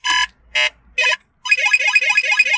Wazealert.wav